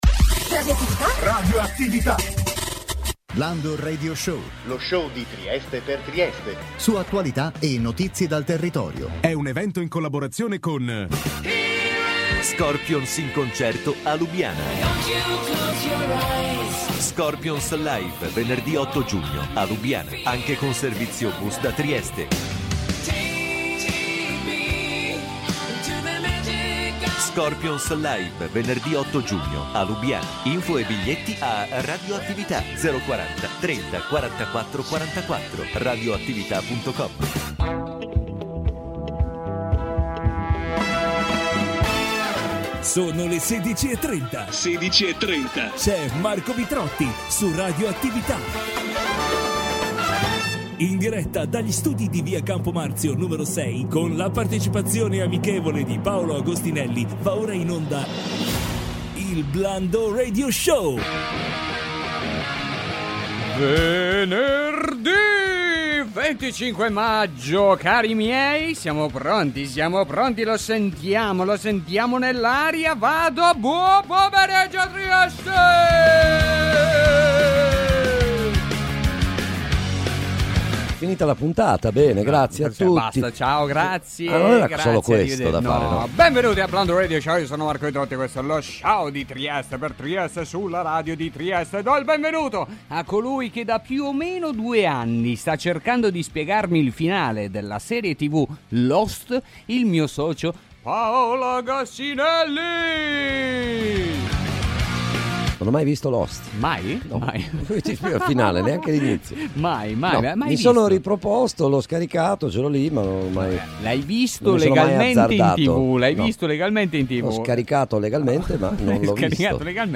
In studio